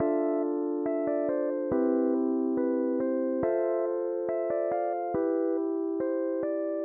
罗德斯
描述：更多的是Lofi/陷阱Rhodes
Tag: 70 bpm Hip Hop Loops Rhodes Piano Loops 1.15 MB wav Key : Unknown FL Studio